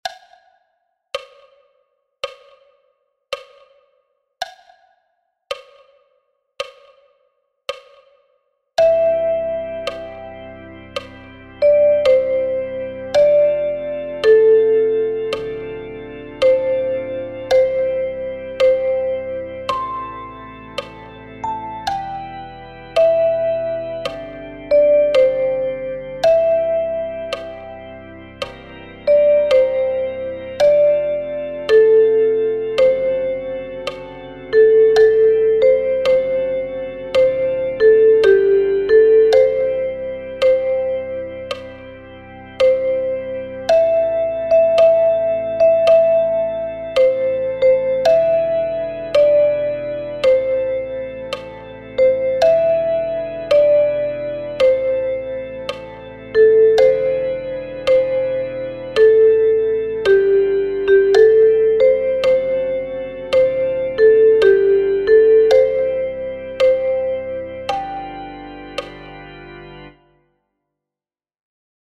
Für Altblockflöte in F.